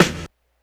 snare04.wav